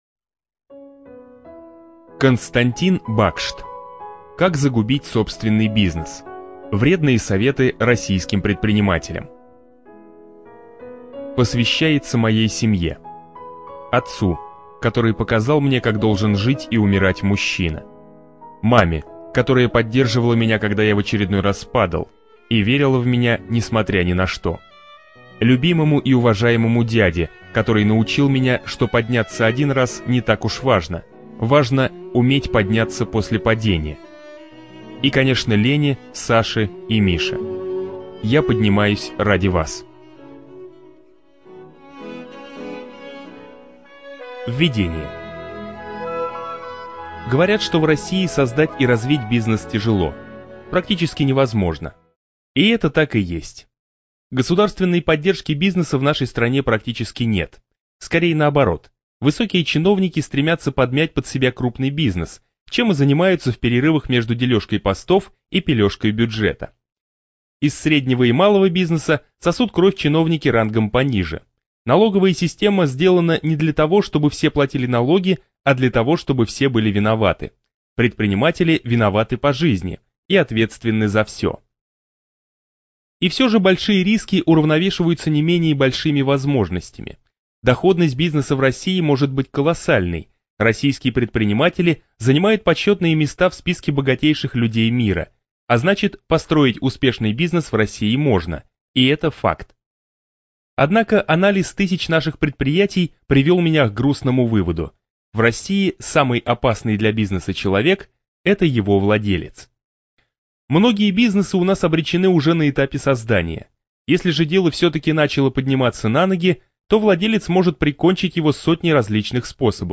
ЖанрДеловая литература